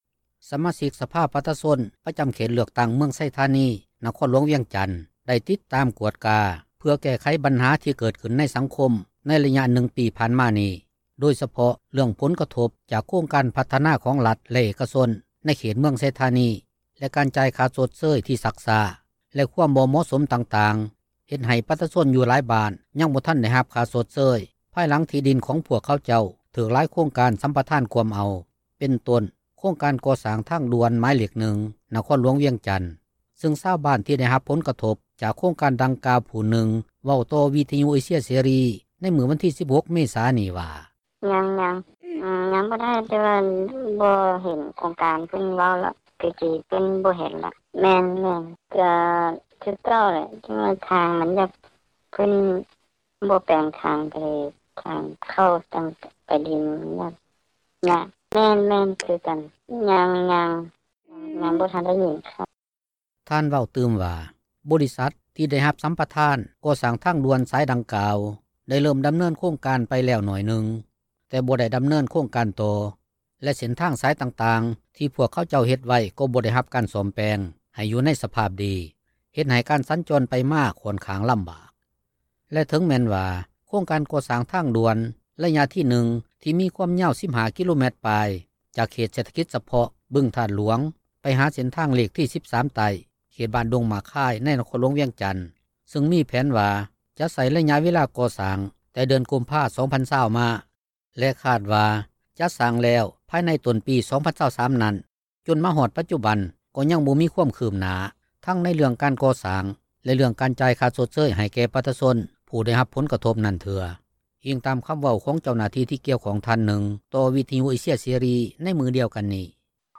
ດັ່ງຊາວບ້ານ ທີ່ໄດ້ຮັບຜົນກະທົບ ຈາກໂຄງການດັ່ງກ່າວ ຜູ້ໜຶ່ງ ເວົ້າຕໍ່ວິທຍຸເອເຊັຽເສຣີ ໃນມື້ວັນທີ 16 ເມສານີ້ວ່າ: